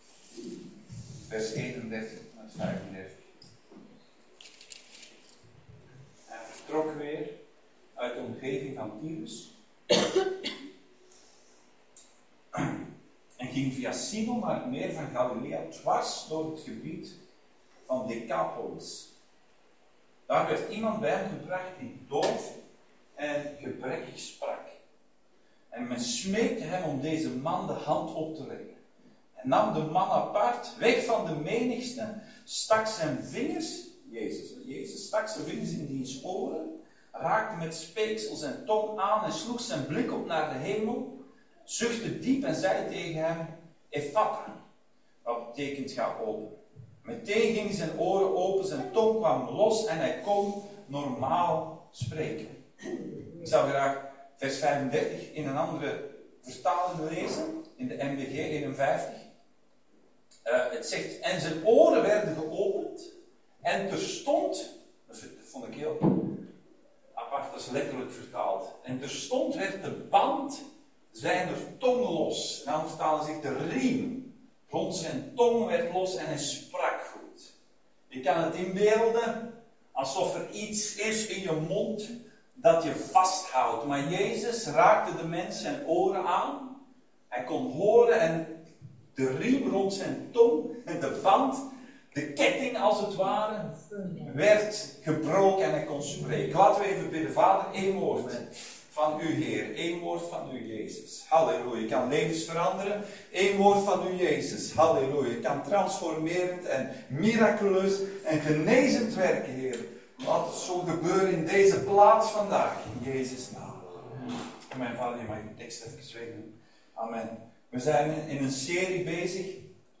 Dienstsoort: Zondag Dienst